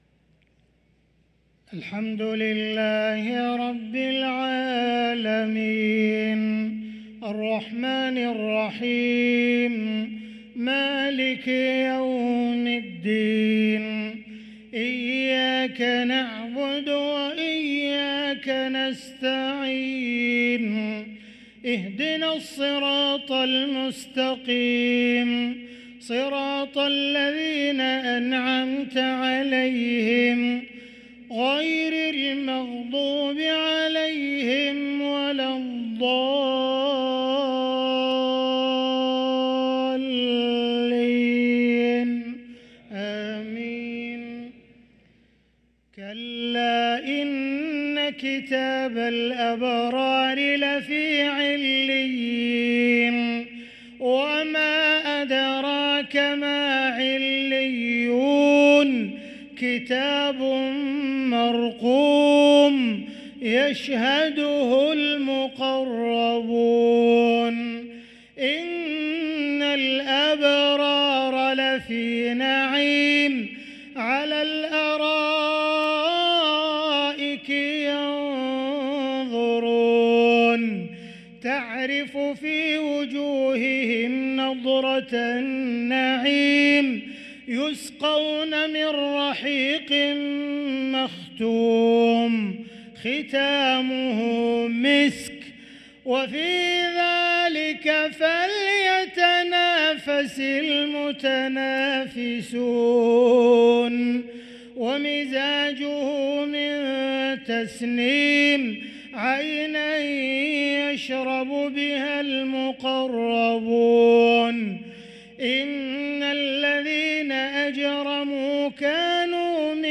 صلاة العشاء للقارئ عبدالرحمن السديس 7 صفر 1445 هـ